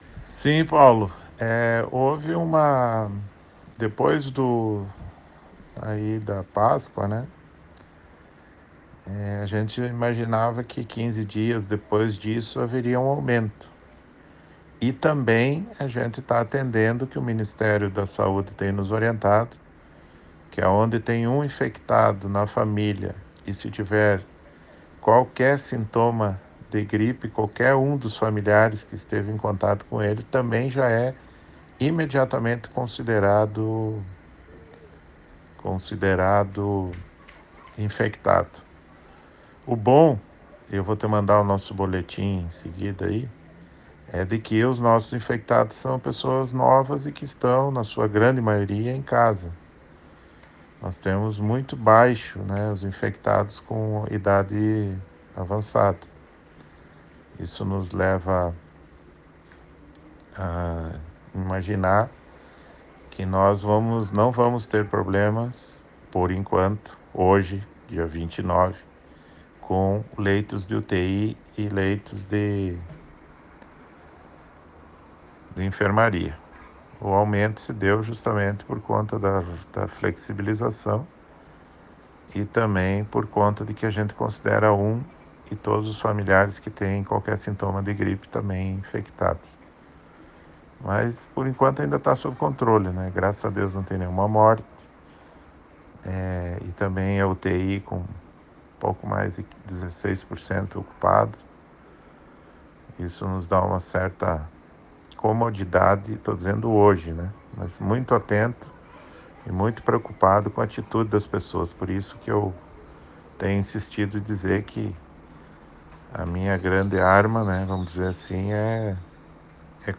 Áudio do prefeito Luciano Buligon:
Luciano-Buligo-prefeito-de-Chapecó.ogg